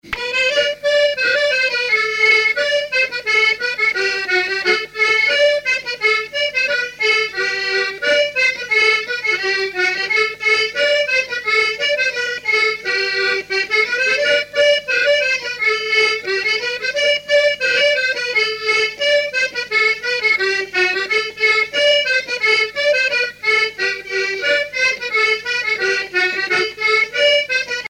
Chants brefs - A danser
danse : sicilienne
musique à danser à l'accordéon diatonique
Pièce musicale inédite